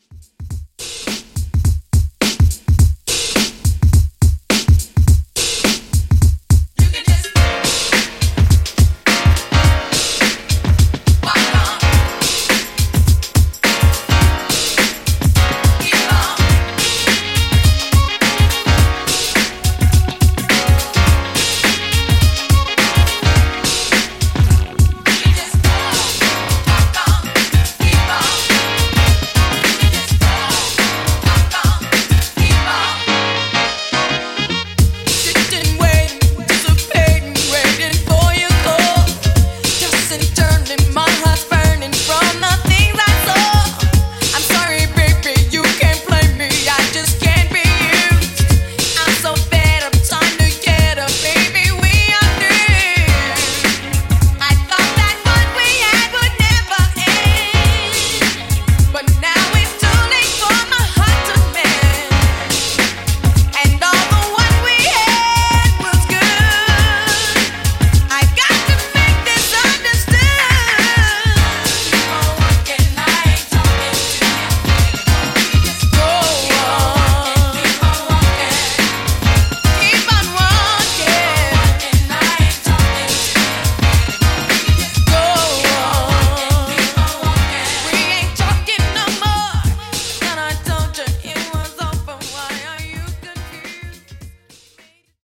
Classic Redrum)Date Added